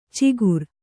♪ cigur